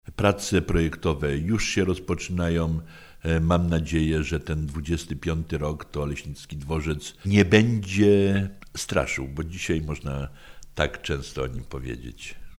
Jan Bronś studio Radio Rodzina Oleśnica
Dworzec PKP w Oleśnicy do 2025 r. ma został wyremontowany. Mówi Jan Bronś.